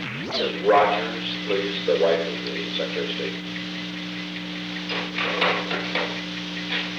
The Old Executive Office Building taping system captured this recording, which is known as Conversation 317-013 of the White House Tapes. Nixon Library Finding Aid: Conversation No. 317-13 Date: January 24, 1972 Time: Unknown between 3:29 pm and 3:32 pm Location: Executive Office Building The President talked with the White House operator.